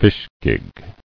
[fish·gig]